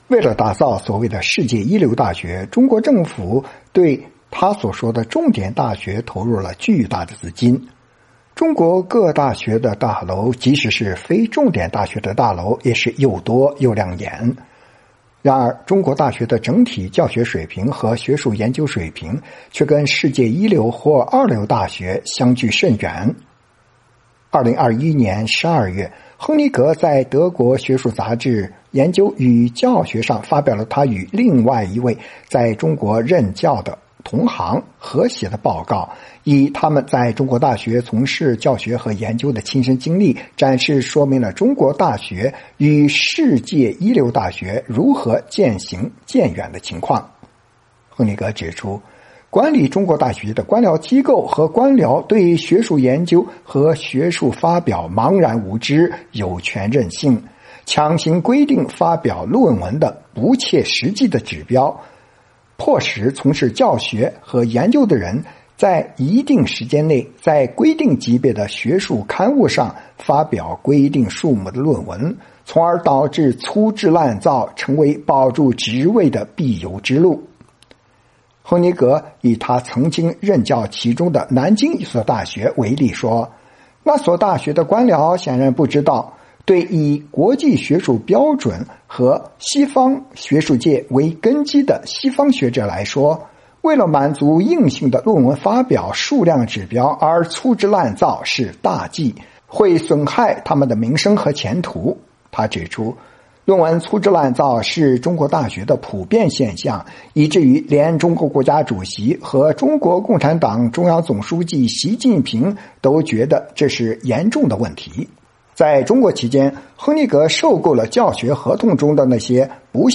美国之音专访